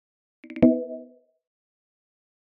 Звуковые эффекты iMac и MacBook